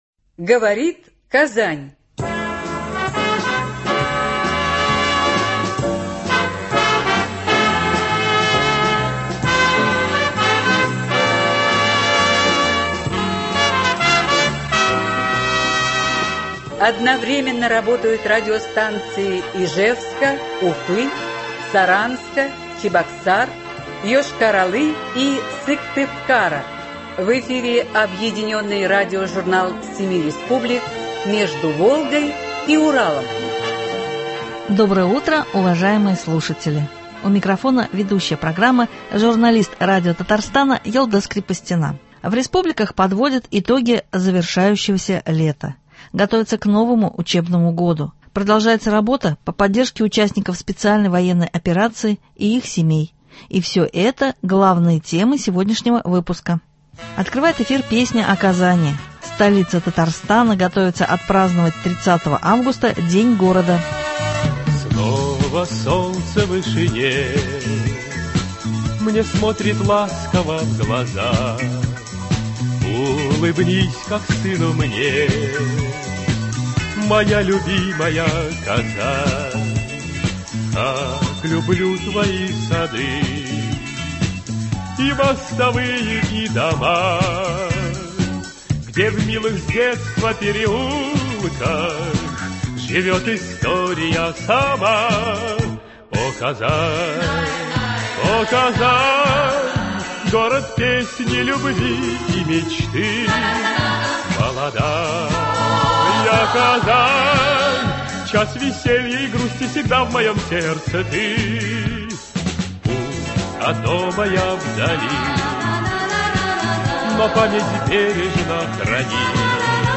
Объединенный радиожурнал семи республик.
Открывает эфир песня о Казани – столица Татарстана готовится отпраздновать 30 августа День города.